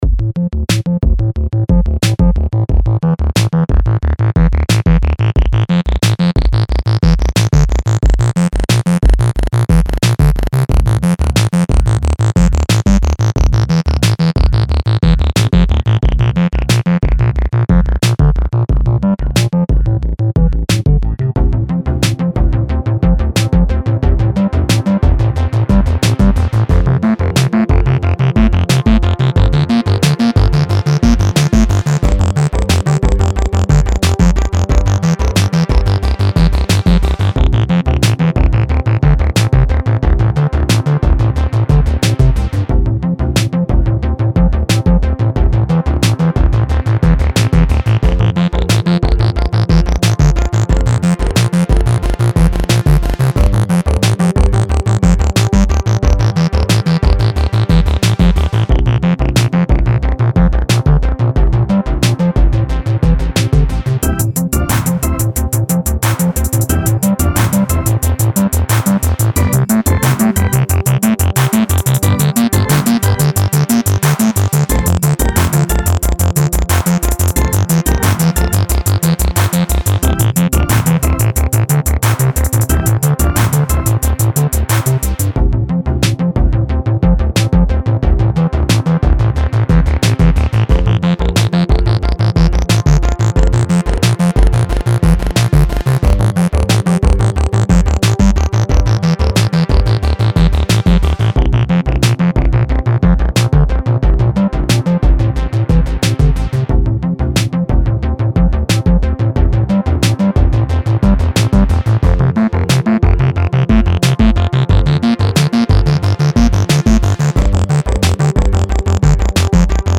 Rap
Hip-hop
Trip-hop